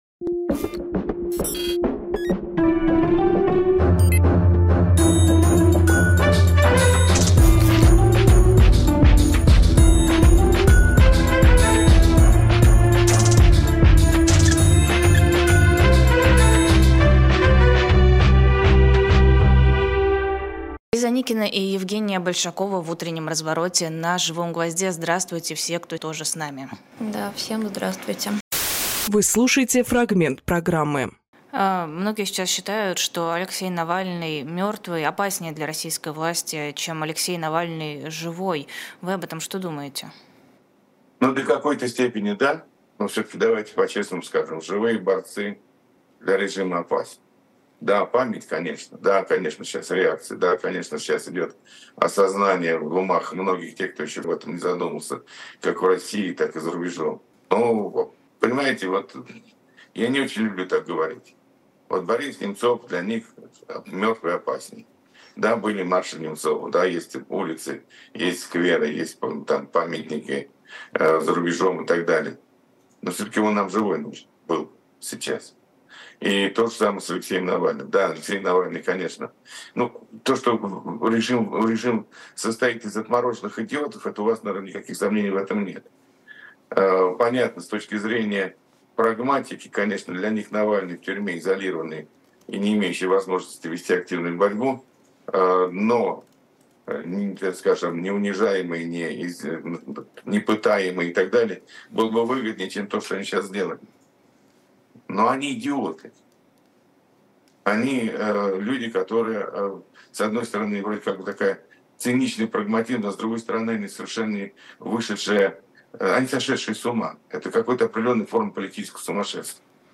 Геннадий Гудковполитик
Фрагмент эфира от 17.02.2024